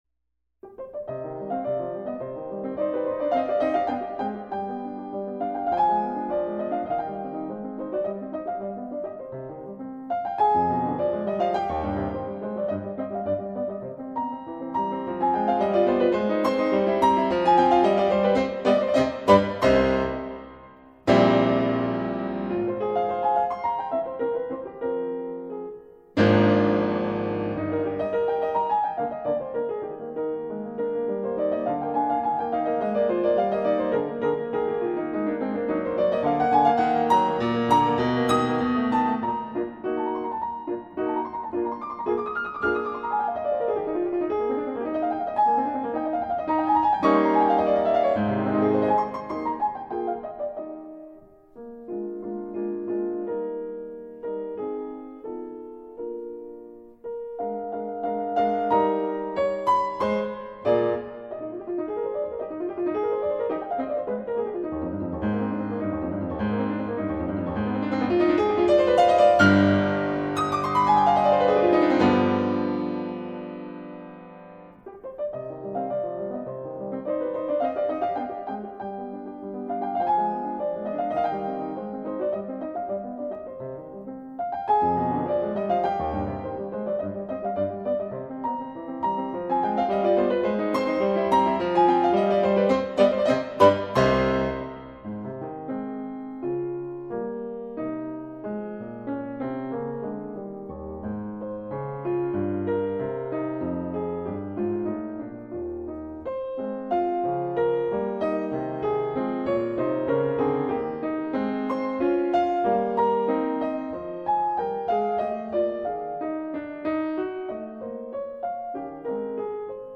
0169-钢琴名曲悲怆奏鸣曲.mp3